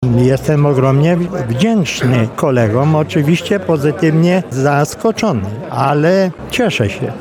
Były marszałek Sejmu Józef Zych został laureatem Nagrody im. Edwarda Wojtasa. Wyróżnienie odebrał podczas tradycyjnego Spotkania Noworocznego Polskiego Stronnictwa Ludowego w Lublinie.